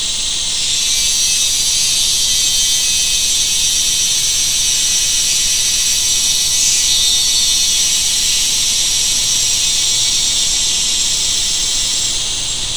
Some species, like the crepuscular cicada heard in Brunei, have a drone with extremely dense harmonics which saturates a broad frequency band.
Crepuscular cicadas have a tymbalization with many harmonics which saturates the majority of the acoustic space from 2 kHz to 8 kHz.